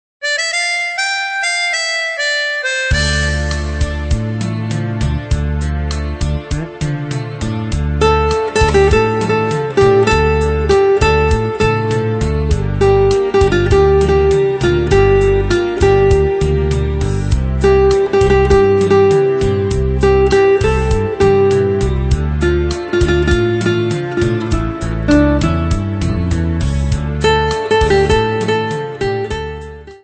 guitar melodies from Poland